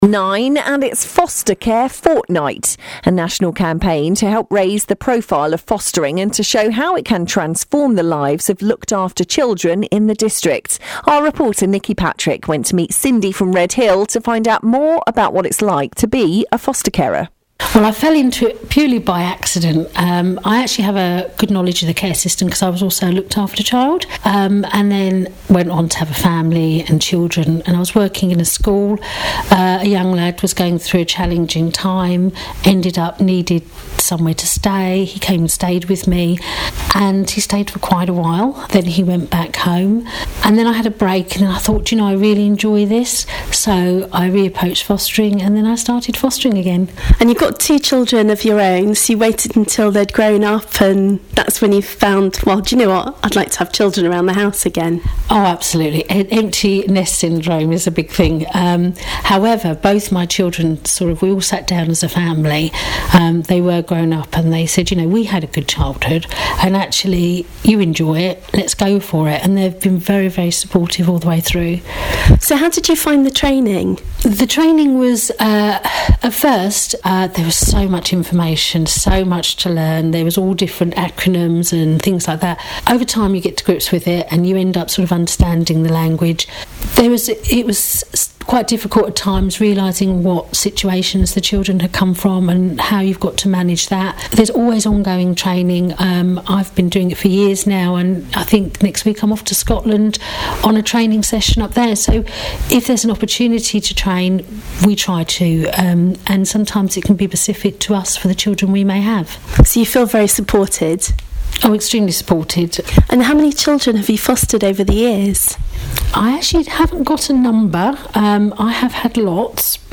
AUDIO: As Foster Care Fortnight gets underway, BBC Surrey talks to a foster carer about her experiences
foster-carer-interview.mp3